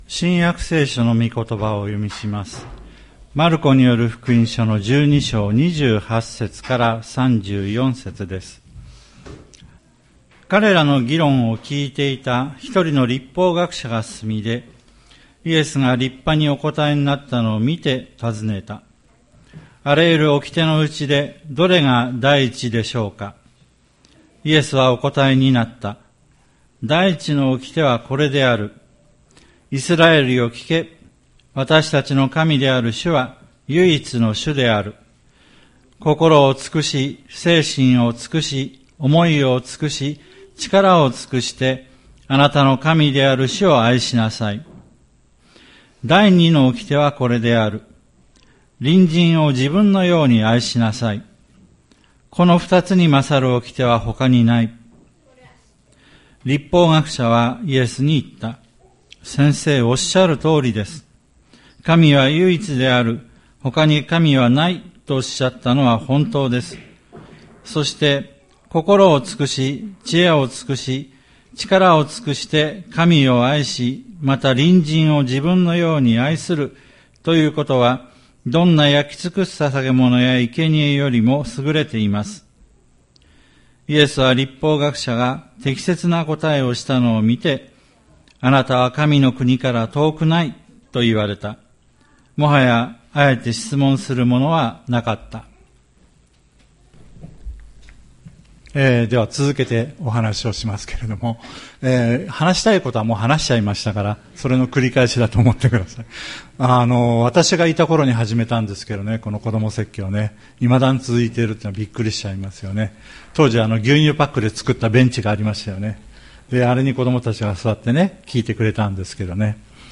千里山教会 2024年09月29日の礼拝メッセージ。